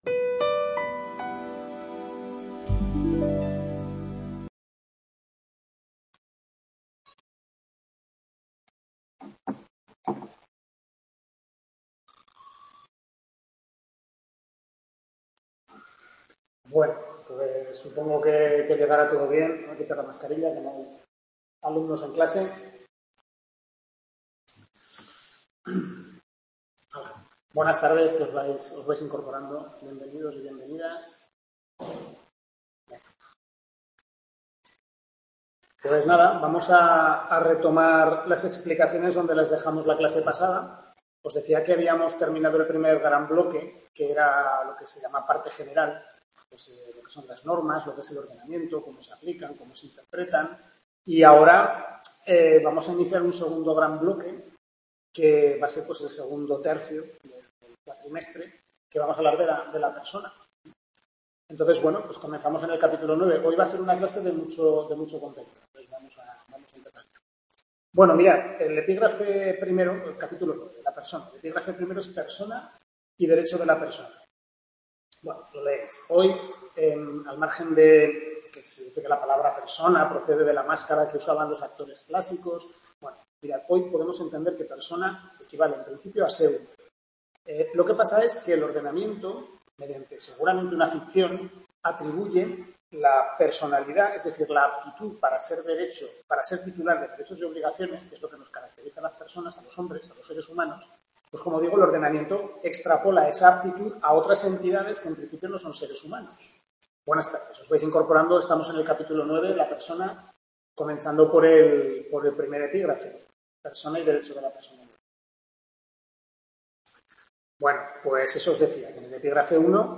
Tutoría del primer cuatrimestre de Civil I